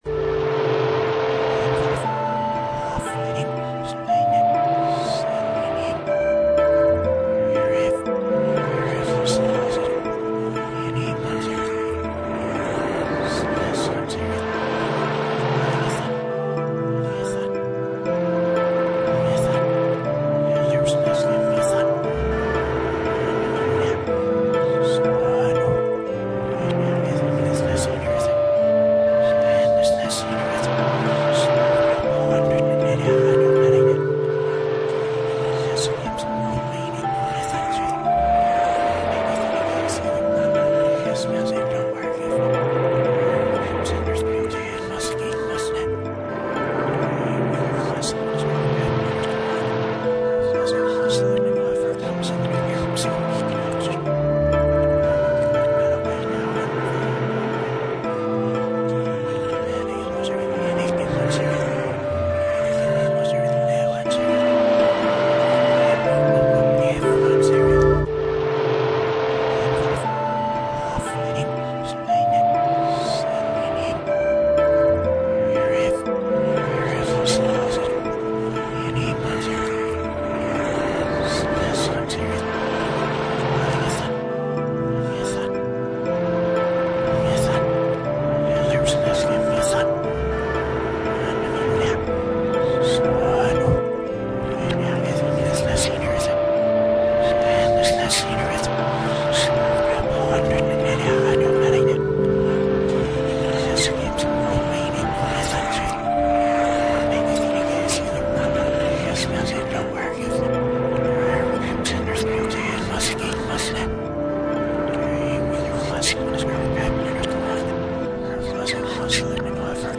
I wanted the underworld theme to be a bit dreamy, rather than downright creepy.
underworldtheme.mp3